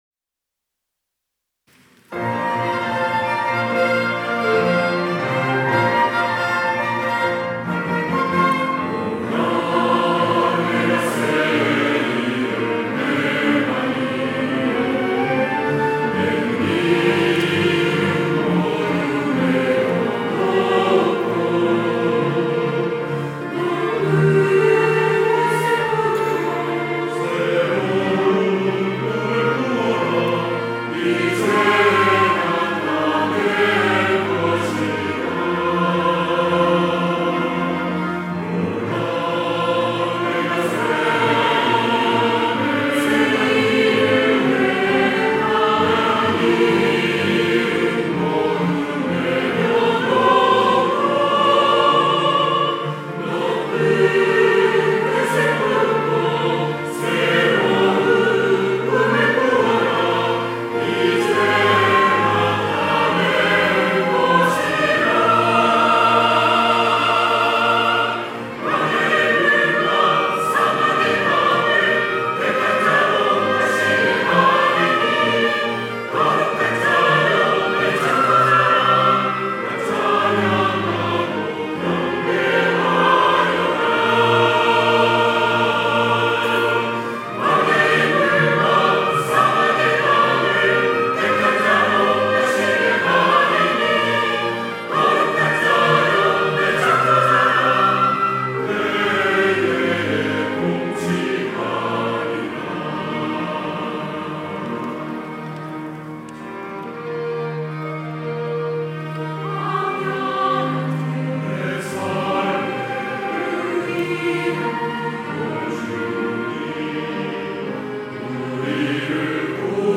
할렐루야(주일2부) - 주를 보라